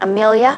synthetic-wakewords
ovos-tts-plugin-deepponies_Kim Kardashian_en.wav